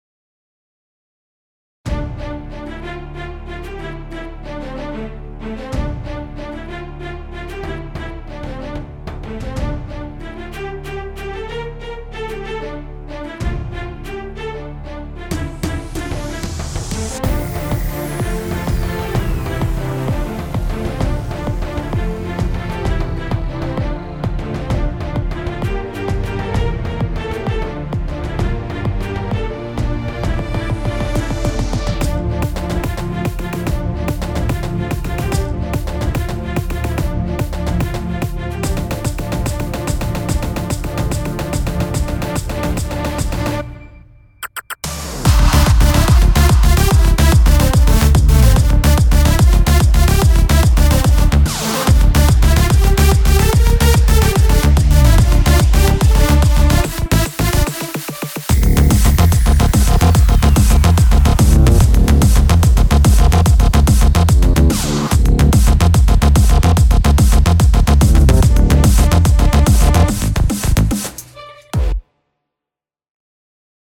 וואו בניה מדויקת ומדהימה, תצליח (תוסיף קצת ריוורב על הכינור)